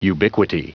Prononciation du mot ubiquity en anglais (fichier audio)
Prononciation du mot : ubiquity